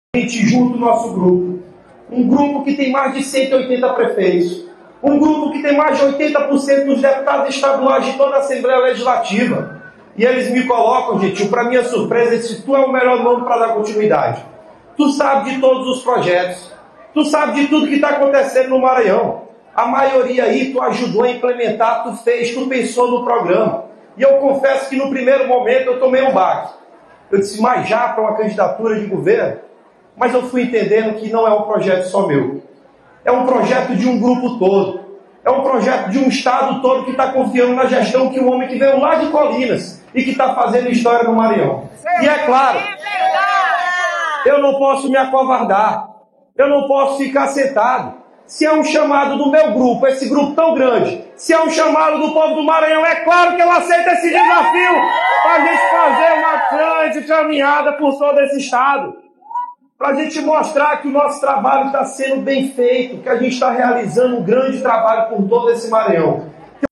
O secretário de Assuntos Municipalistas, Orleans Brandão, declarou publicamente que aceita ser o candidato do grupo do governador Carlos Brandão ao Governo do Maranhão. A confirmação ocorreu durante um encontro político em Caxias, organizado pelo ex-prefeito Fábio Gentil, com presença de prefeitos, deputados e lideranças regionais.